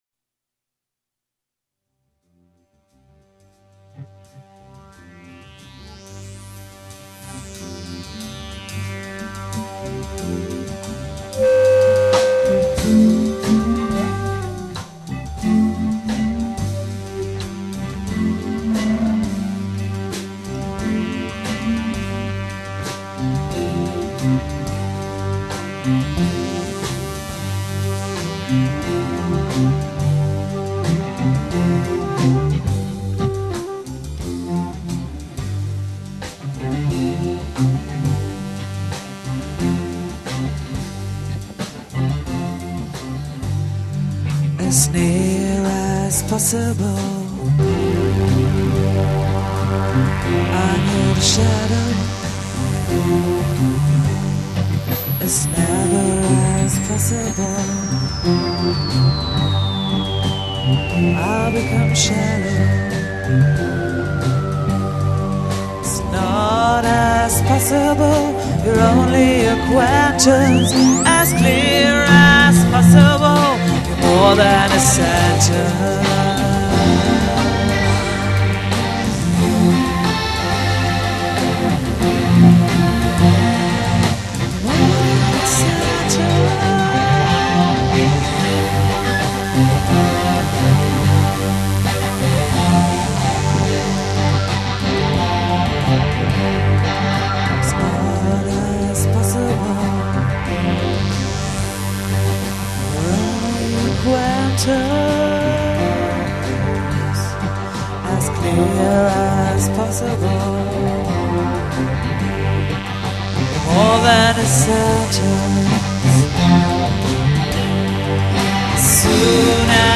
Etwas publikumstauglicher als die anderen Sachen, die ich Euch bislang vorgestellt habe...
Die "normale" Band nennt sich "Creep"... das Stück nennt sich "As soon as possible". Auch dieses Stück haben wir im Proberaum mitgeschnitten...